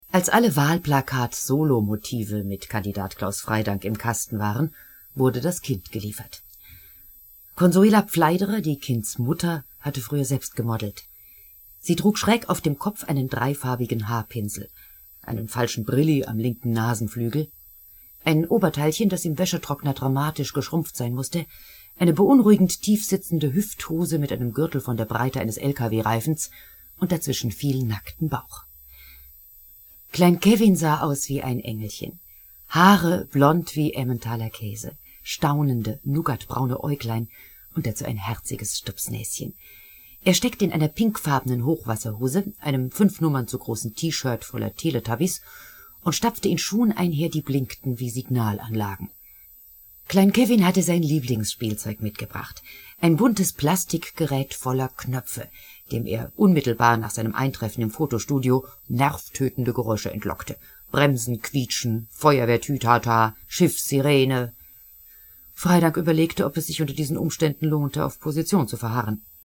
Schauspielern, Moderatorin, Sprecherin. Synchronsprecherin. Hörbuch-Sprecherin.
Sprechprobe: Werbung (Muttersprache):